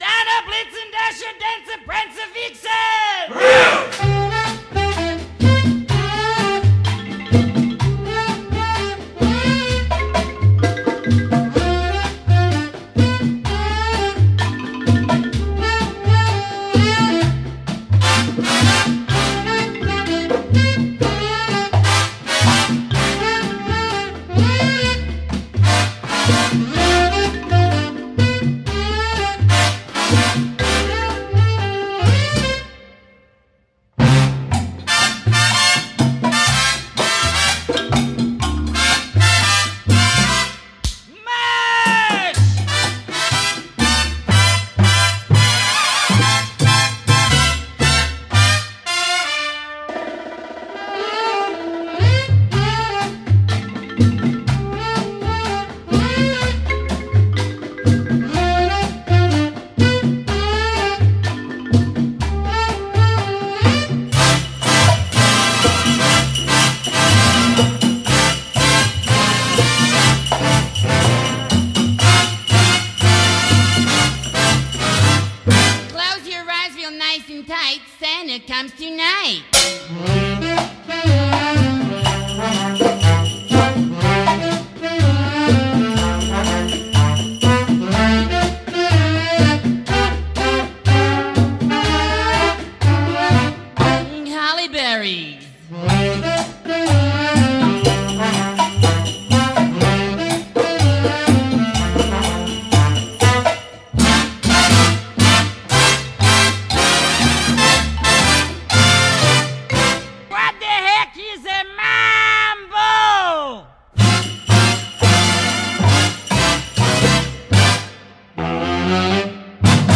BPM108-126
Audio QualityPerfect (Low Quality)